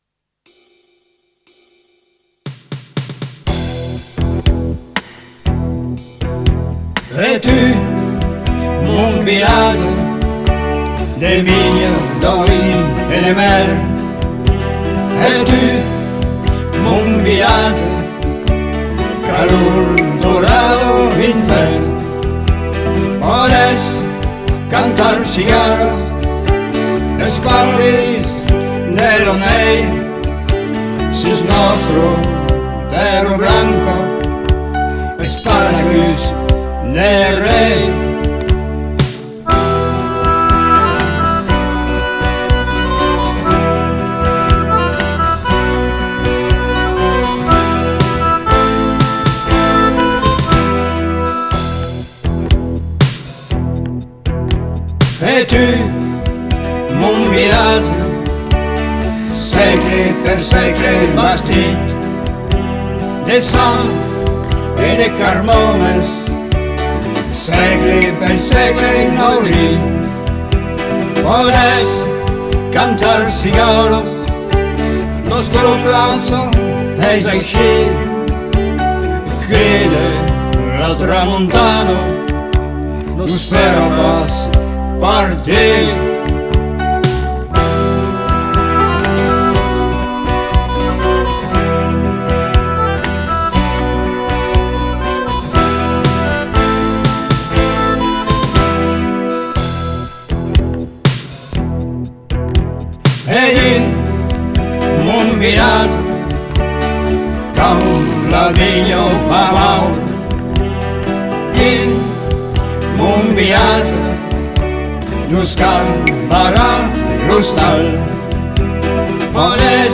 lors de la tournée QUEBEC_ MONTREAL
mais remixée en 2021